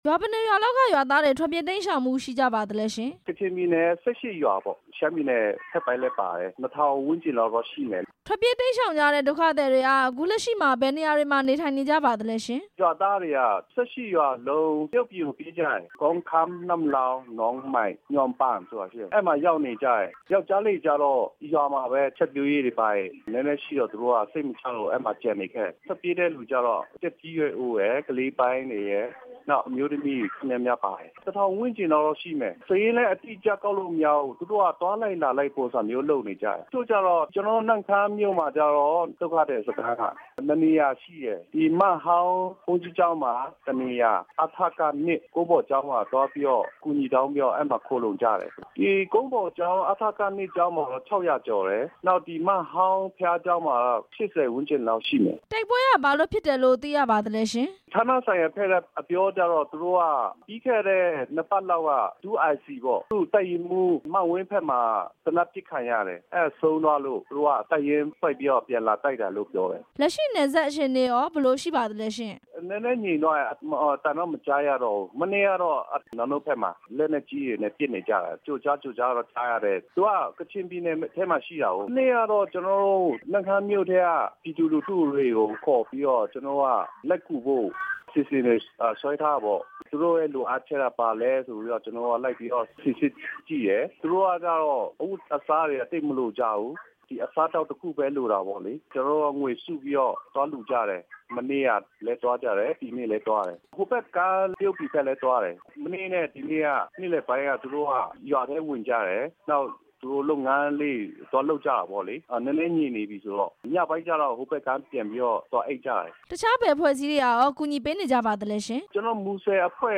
လွှတ်တော်ကိုယ်စားလှယ် ဦးစိုင်းတင်ဦးနဲ့ မေးမြန်းချက် နားထောင်ရန်